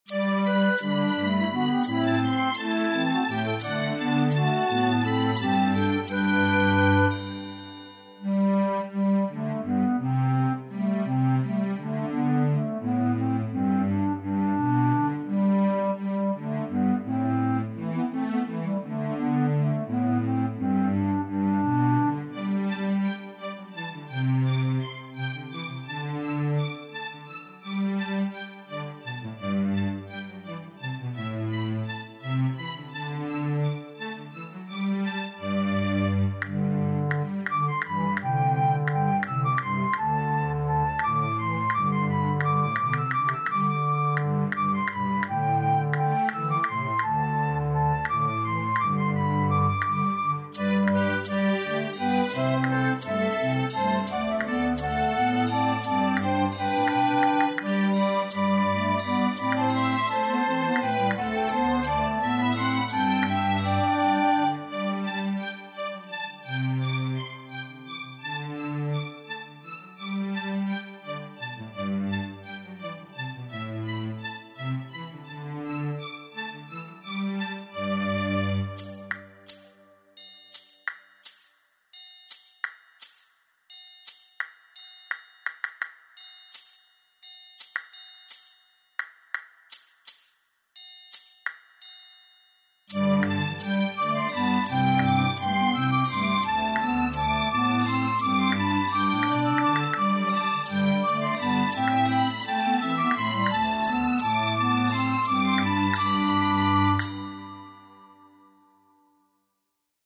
Pavadījuma demo